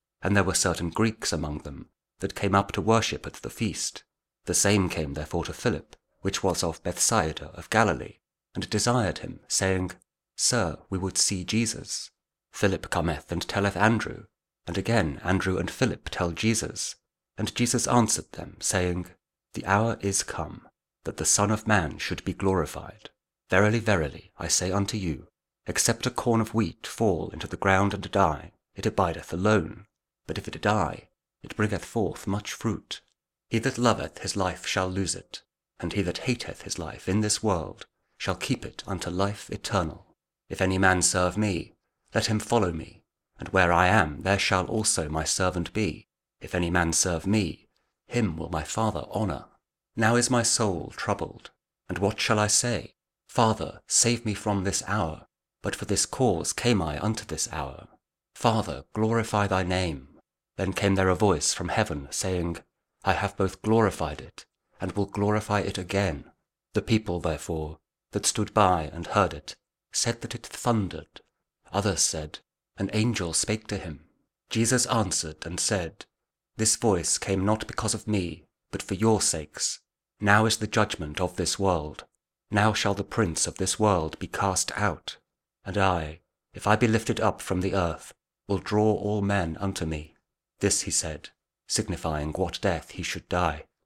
John 12: 20-33 – Lent Week 5, Sunday (Year B) (Audio Bible, Spoken Word)